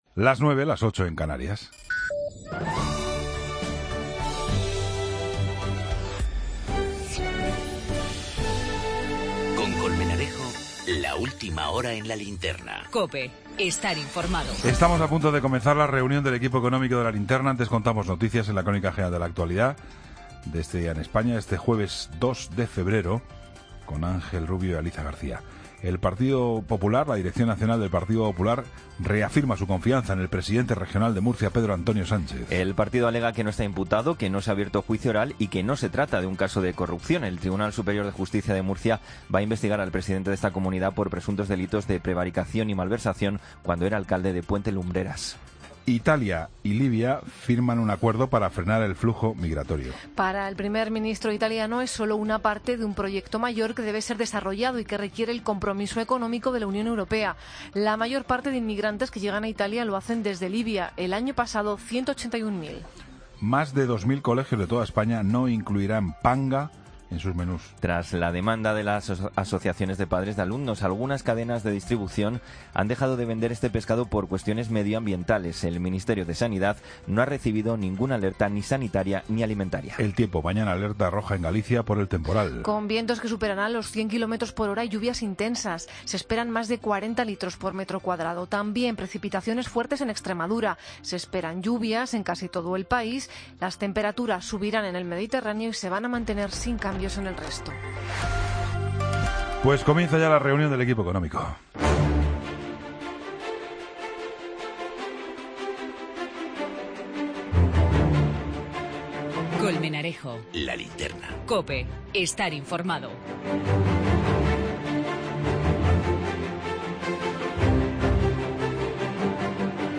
La tertulia económica